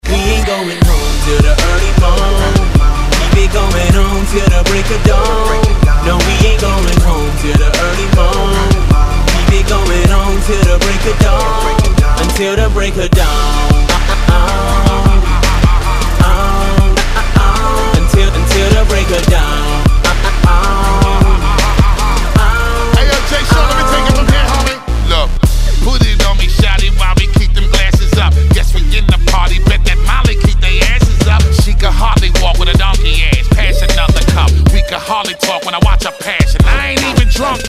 • Качество: 192, Stereo
Крутой репчик